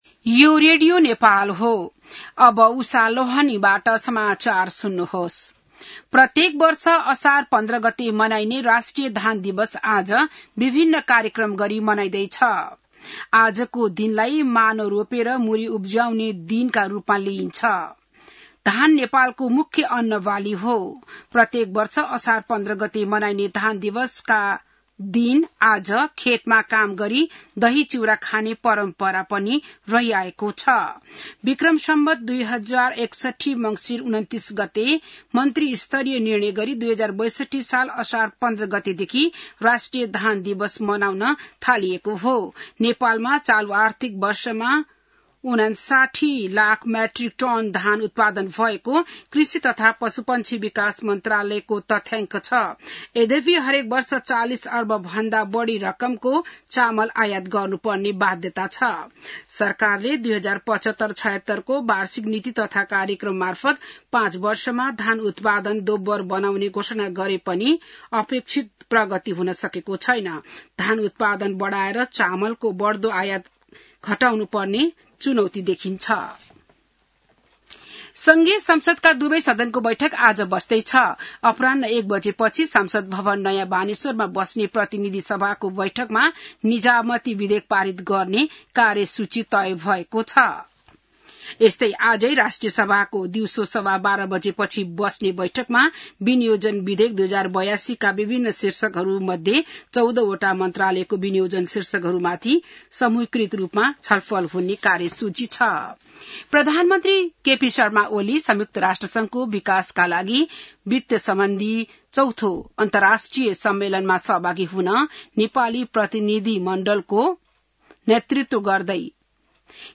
बिहान १० बजेको नेपाली समाचार : १५ असार , २०८२